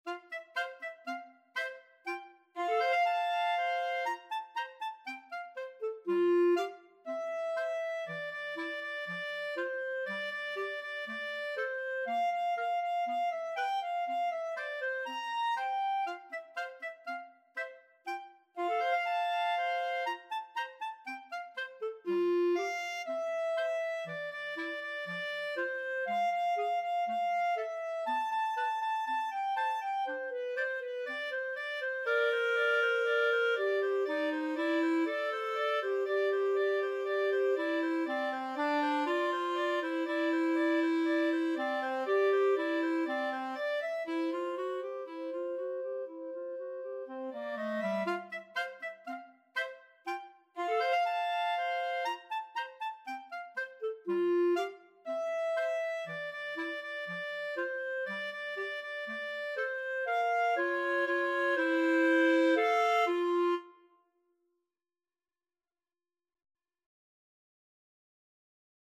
Allegro (View more music marked Allegro)
4/4 (View more 4/4 Music)
Clarinet Duet  (View more Intermediate Clarinet Duet Music)
Classical (View more Classical Clarinet Duet Music)